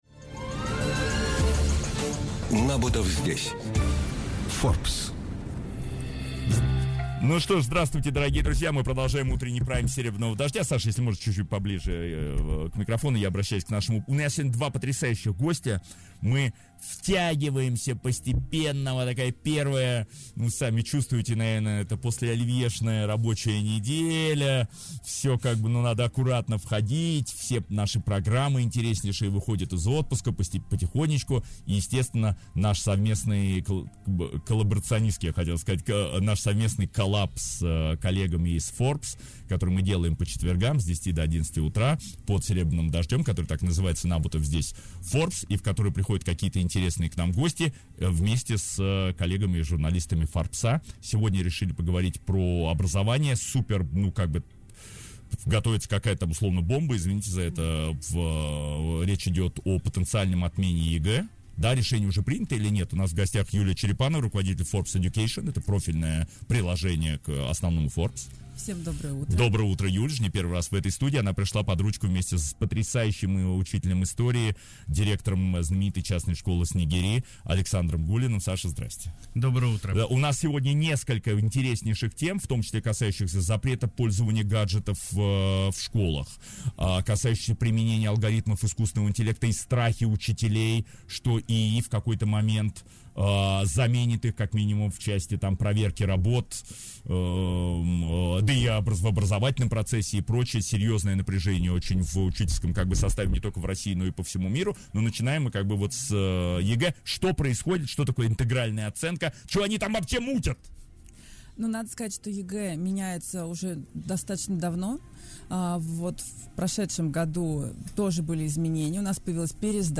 Каждый четверг в утреннем эфире радиостанции «Серебряный дождь» — программа «Набутов здесь. Forbes». Экономика, финансы, общество, технологии и наука.